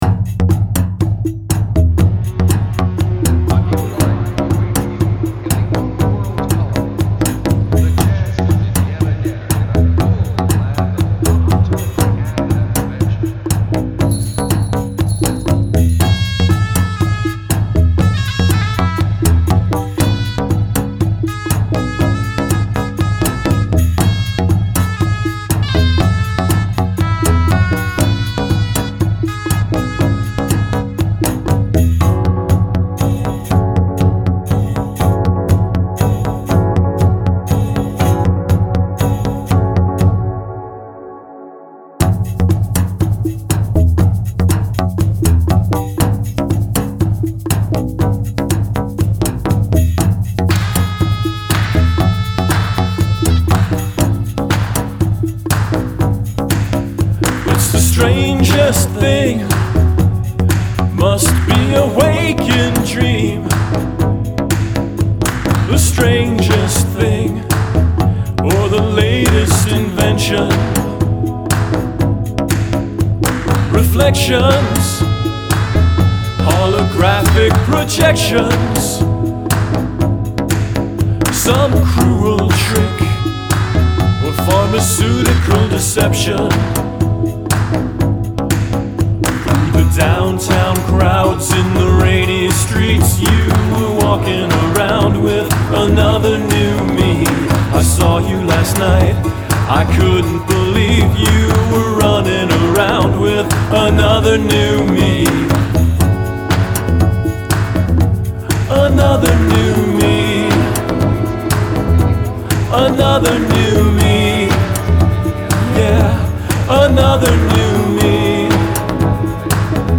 Stark and minimal. All of the sounds are acoustic or physical in origin - there are no synthesizers or electric guitars, drum machines, or drum kits. The closest thing to that is electric piano.